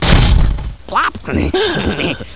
airdoor2.wav